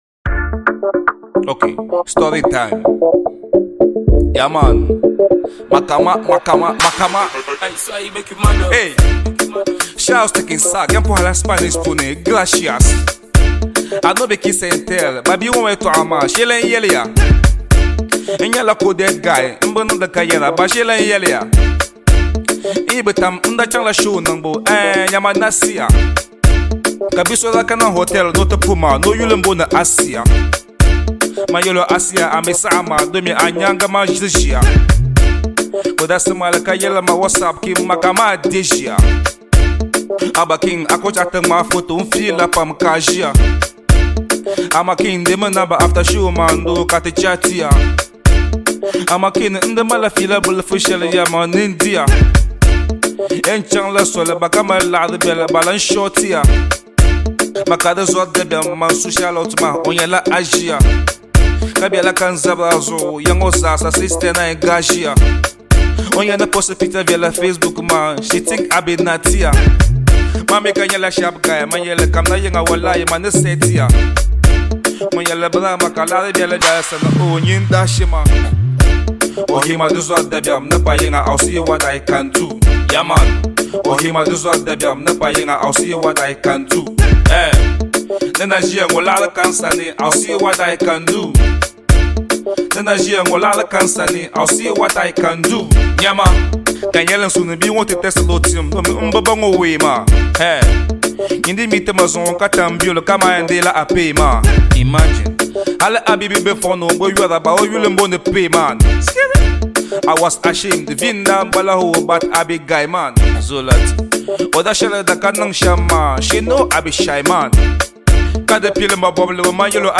Ghanaian rapper
instrumentals
hardcore rap freestyle